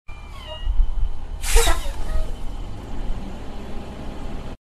دانلود صدای باد ترمز کامیون – تریلی 1 از ساعد نیوز با لینک مستقیم و کیفیت بالا
جلوه های صوتی